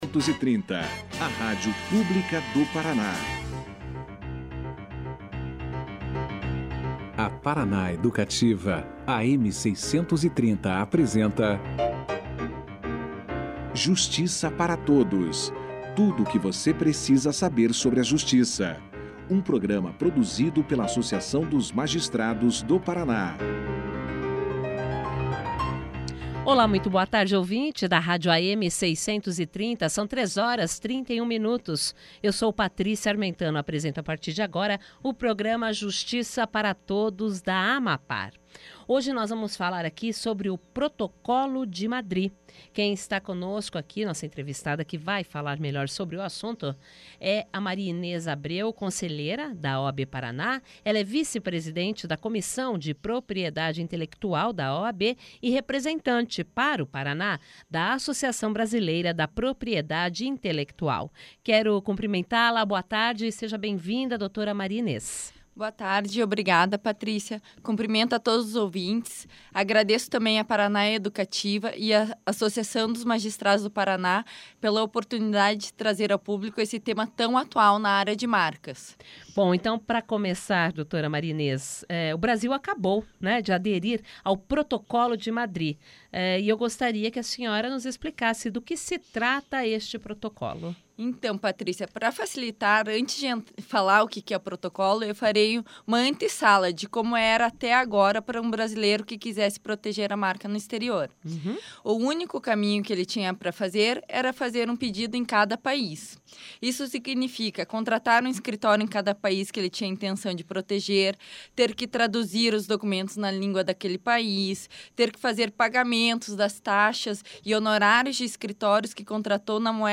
Um acordo que prevê mais segurança jurídica contra falsificações, pirataria e apropriações de má fé de um produto ou serviço brasileiro e que deve impulsionar uma maior exportação de produtos nacionais. Confira aqui a entrevista na íntegra.